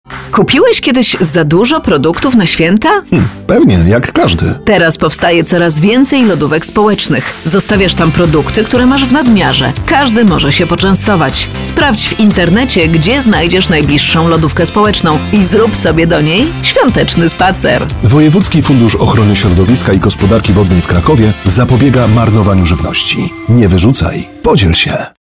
Poniżej znajdziecie Państwo spoty radiowe dotyczące sposobów na wykorzystanie zapasów żywności: